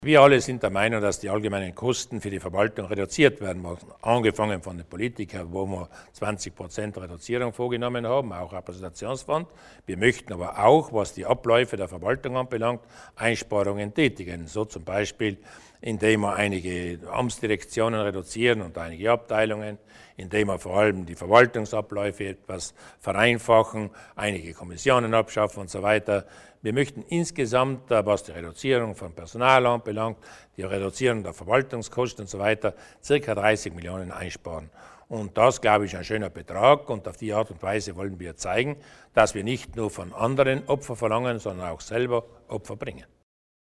Landeshauptmann Durnwalder erläutert die Maßnahmen zur Kosteneinsparung